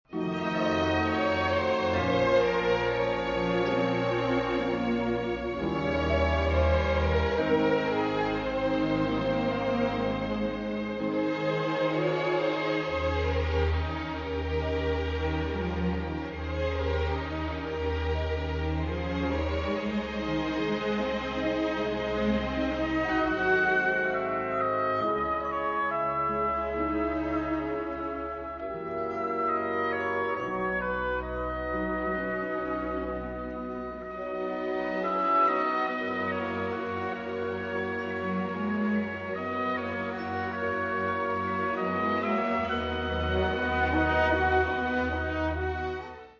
The moving, emotional theme from